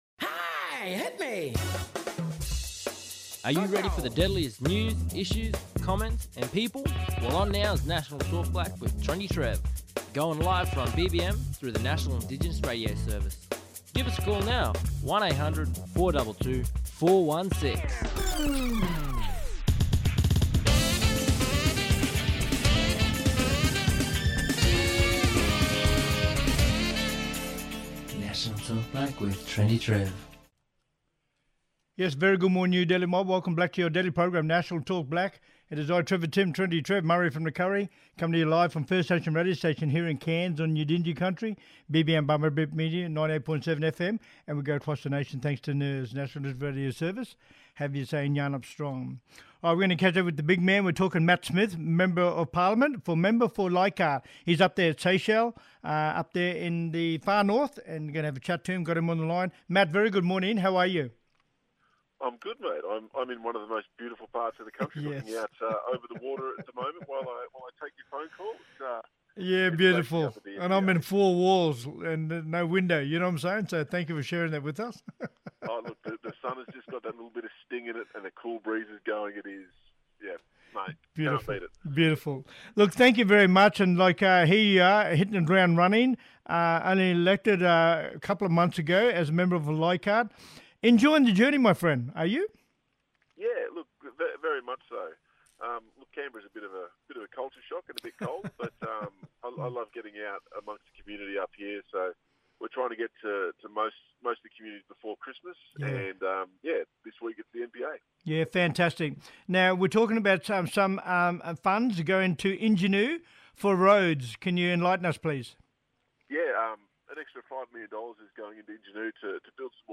Matt Smith MP, Member for Leichhardt, talking about the Funding to strengthen Far North Queensland and Torres Strait against Disasters.